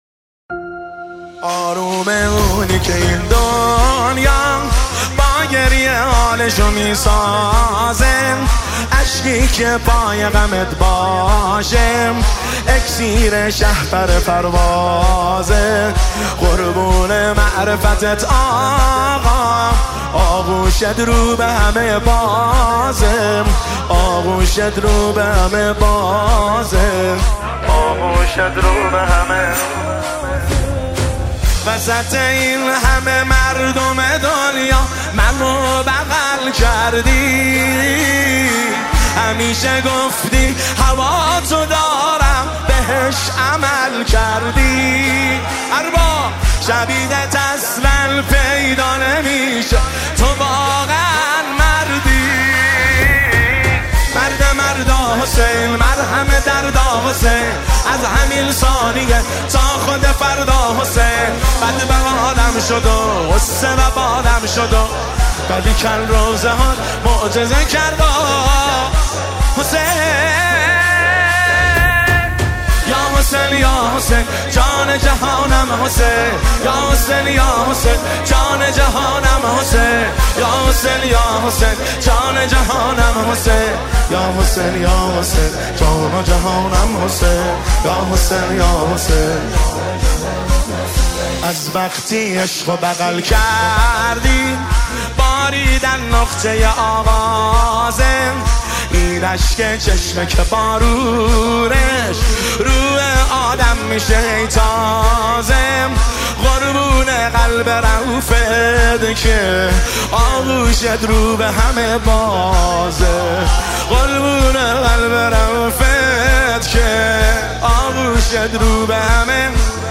نماهنگ دلنشین
مداحی امام حسین